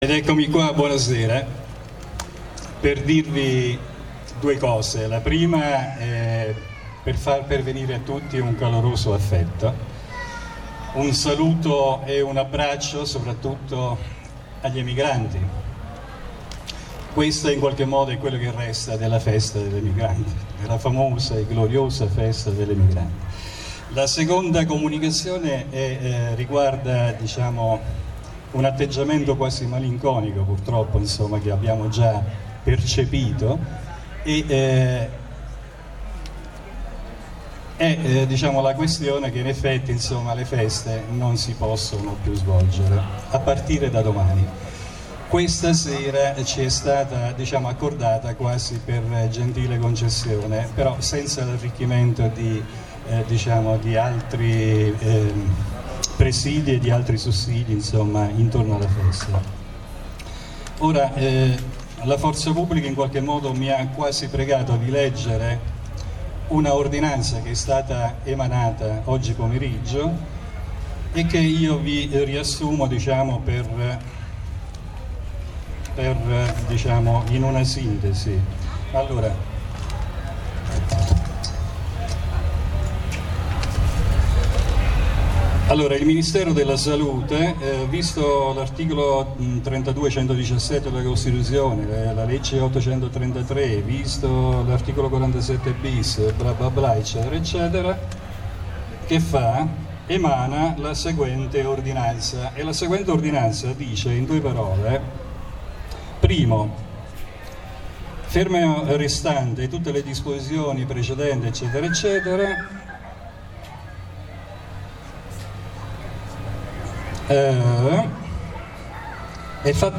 Comizio